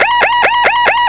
chopperDamage.ogg